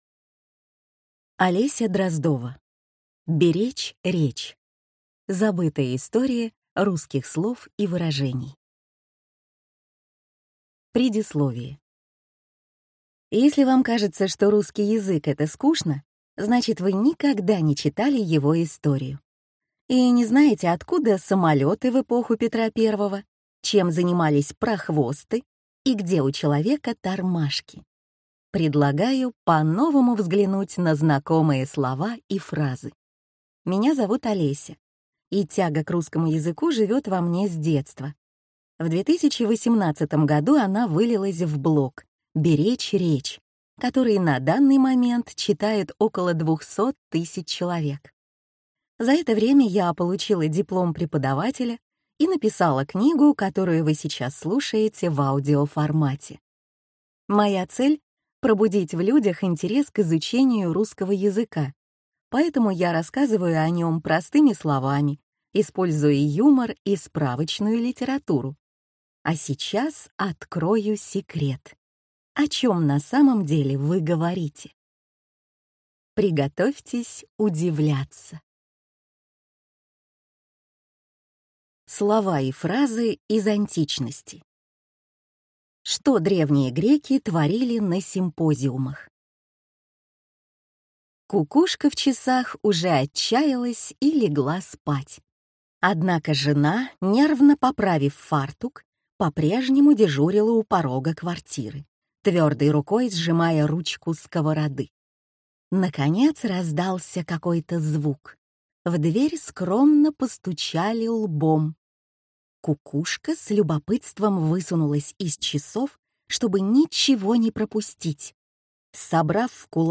Аудиокнига Беречь речь. Забытая история русских слов и выражений | Библиотека аудиокниг